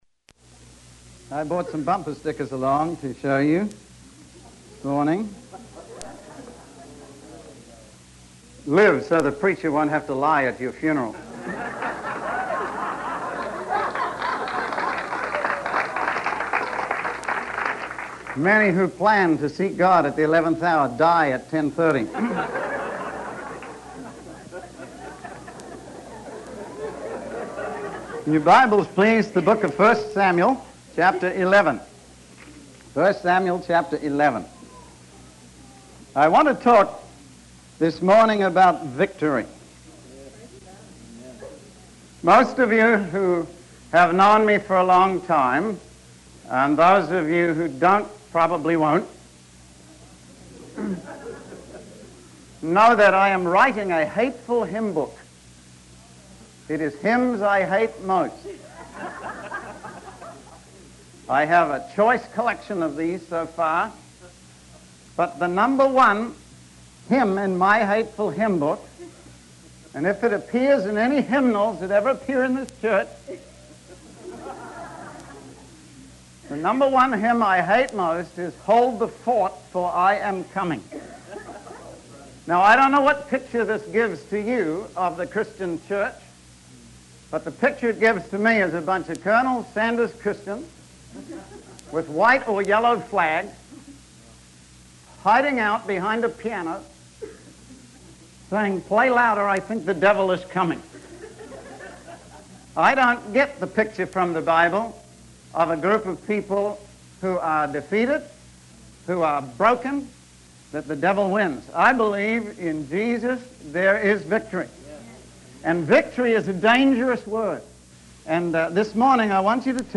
In this sermon, the preacher discusses the story of Saul and the Israelites facing a dangerous enemy named Nehash.